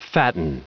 Prononciation du mot fatten en anglais (fichier audio)
Prononciation du mot : fatten